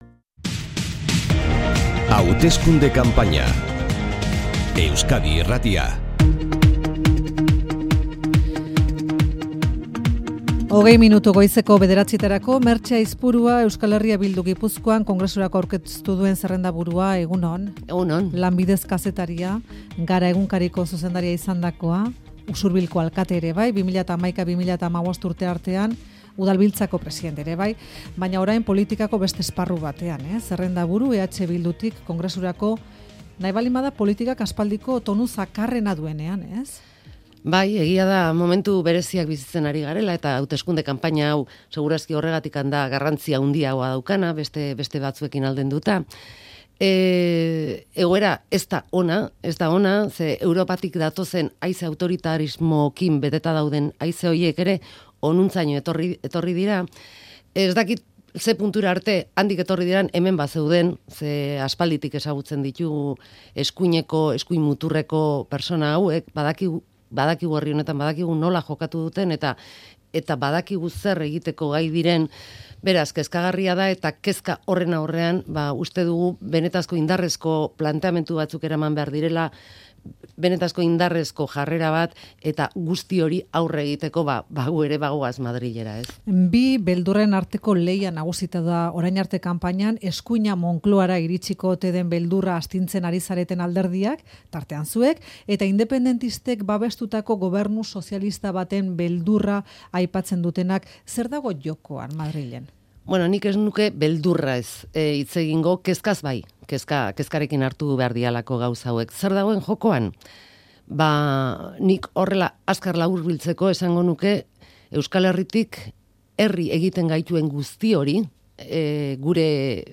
Hauteskunde elkarrizketa: EH Bildu